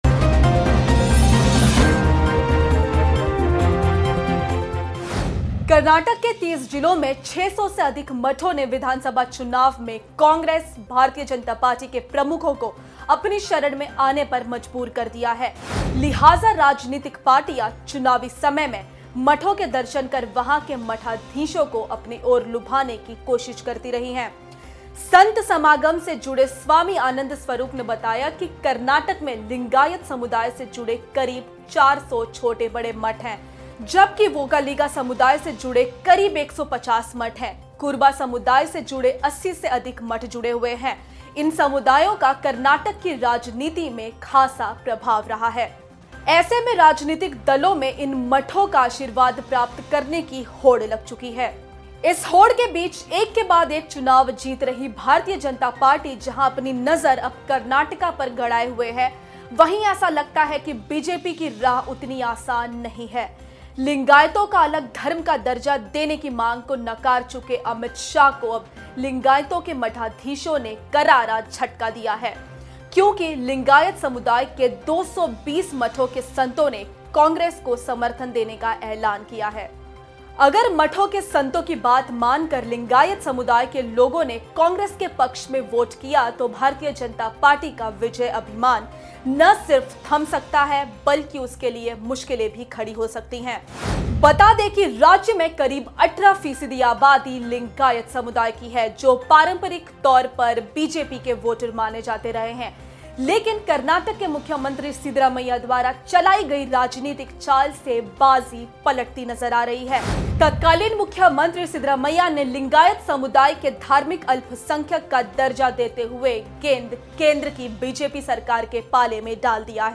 News Report / कर्नाटक चुनाव में बीजेपी को बड़ा झटका: 220 मठों ने कांग्रेस को समर्थन देने का किया ऐलान